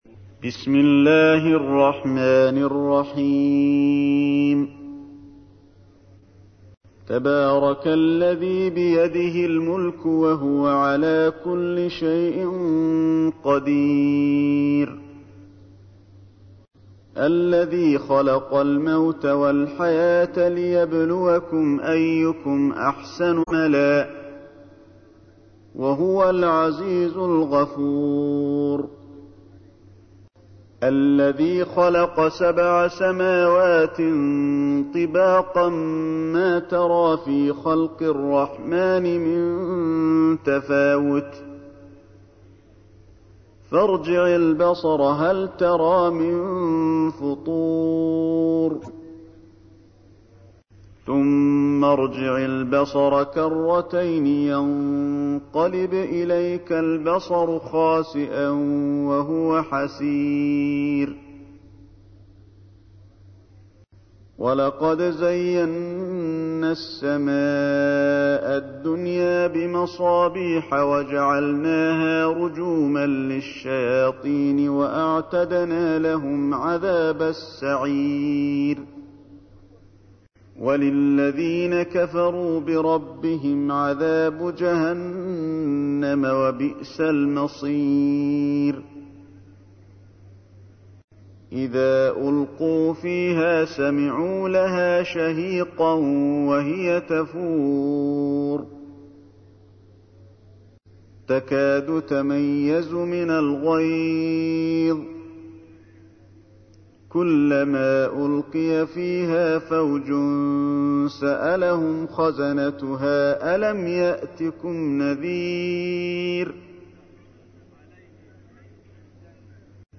تحميل : 67. سورة الملك / القارئ علي الحذيفي / القرآن الكريم / موقع يا حسين